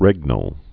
(rĕgnəl)